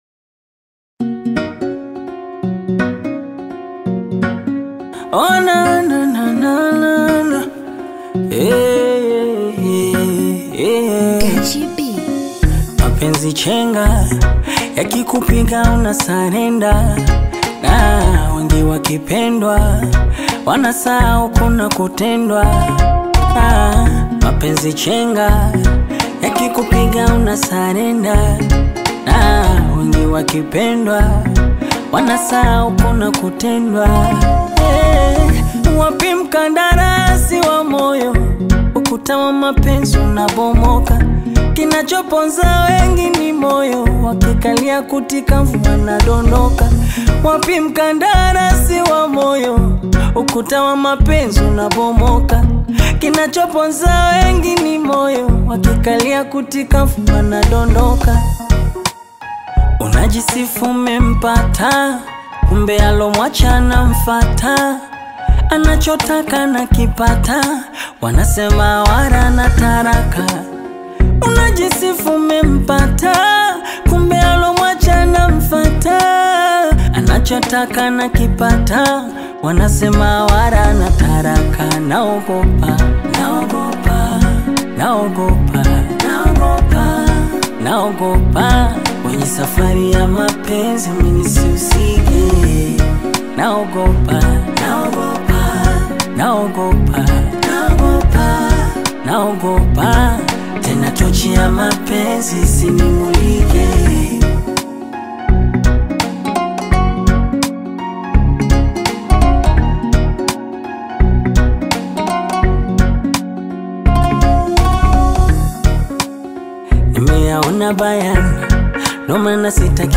talented singer
ballad single